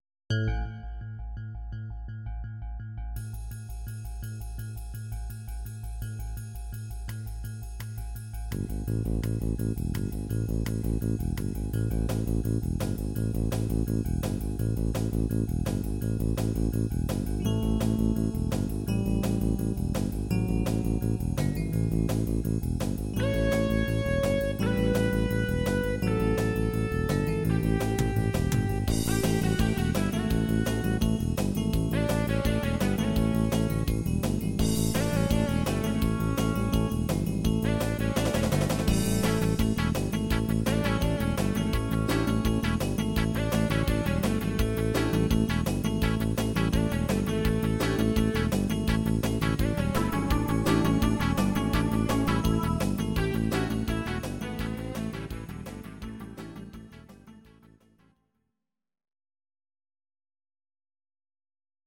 Audio Recordings based on Midi-files
Our Suggestions, Pop, Rock, 1970s